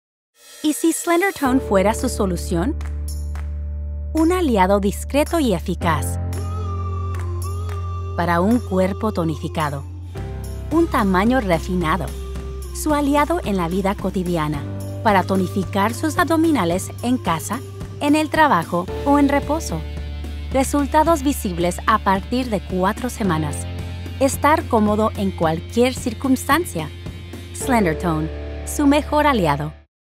Female Voice Over, Dan Wachs Talent Agency.
Bilingual Voice Actor.  English, Neutral Spanish, Columbian and Mexican Dialects.
Commercial #2 - Spanish